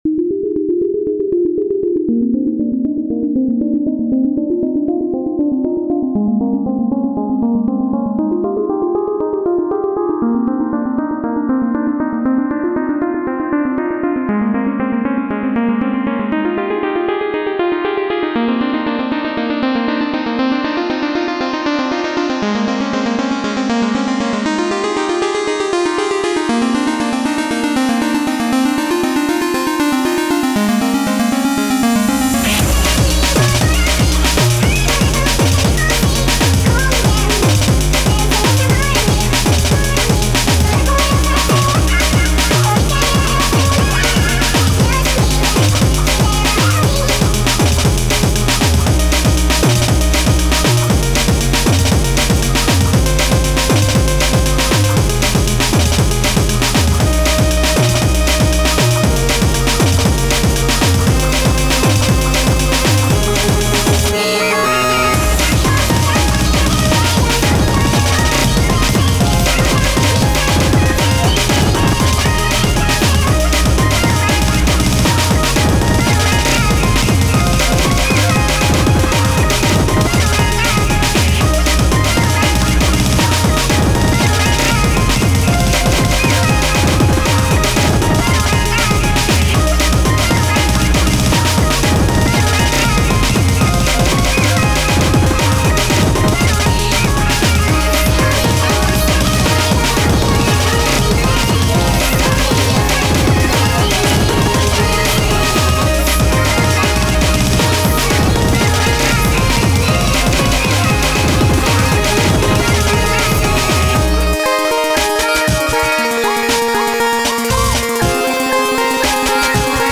dancemusic breakcore remix